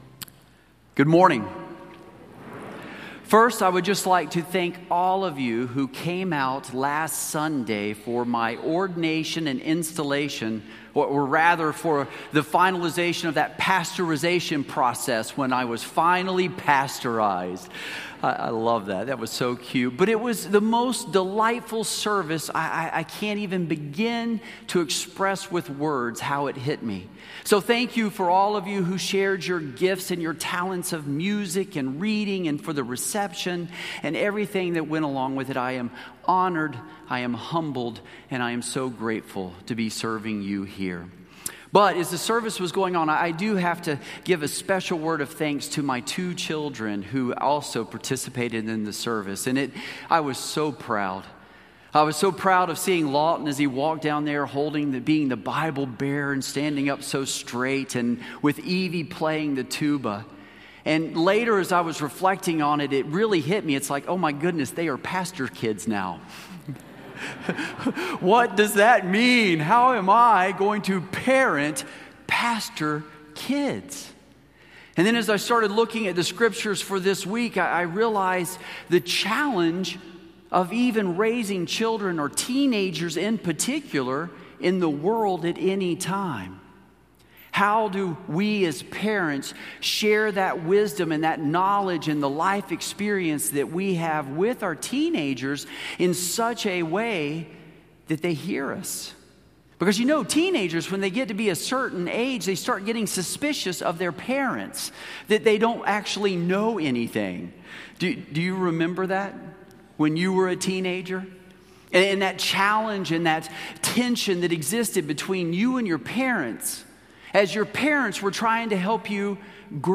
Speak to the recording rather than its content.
Or, if you prefer just audio from the 8:30 service: